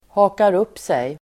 Uttal: [ha:kar'up:sej]